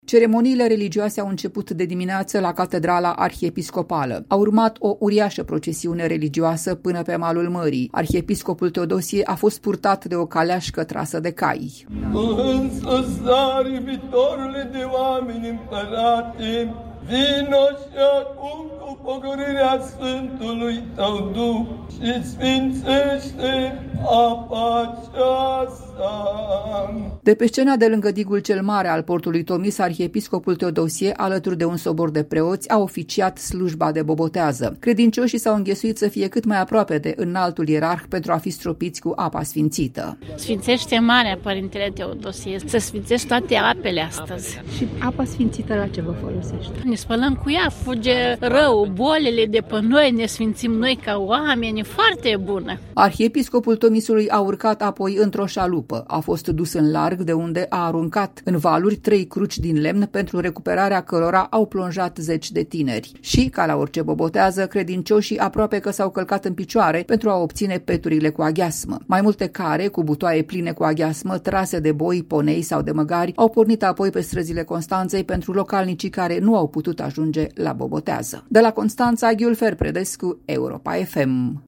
Mii de constănțeni s-au adunat, de Bobotează, în portul turistic Tomis, din Constanța, la Slujba de Sfințire a apelor mării, oficiată de arhiepiscopul Teodosie al Tomisului.
Participantă la slujba de Bobotează: „Sfințește marea părintele Teodosie, se sfințesc toate apele astăzi”.